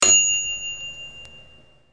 电子提示音.wav